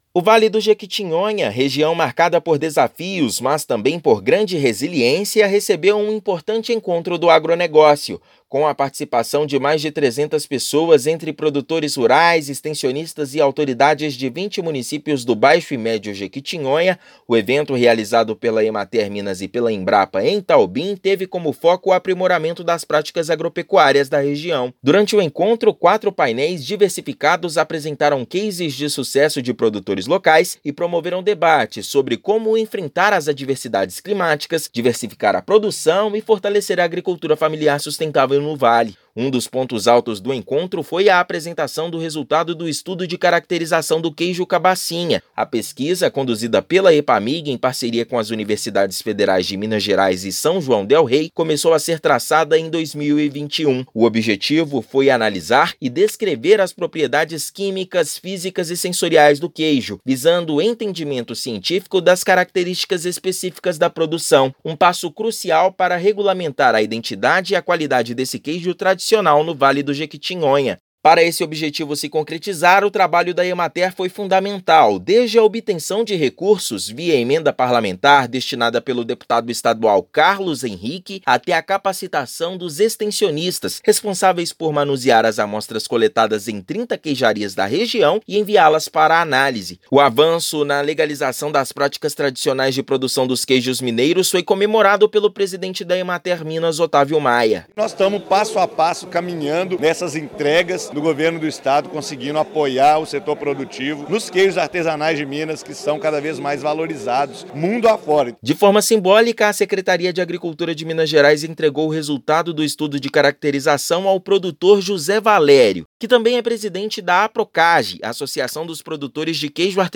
Produtores receberam a conclusão do estudo durante o Encontro do Agronegócio do Vale do Jequitinhonha realizado em Itaobim. Ouça matéria de rádio.